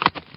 PixelPerfectionCE/assets/minecraft/sounds/mob/horse/soft5.ogg at mc116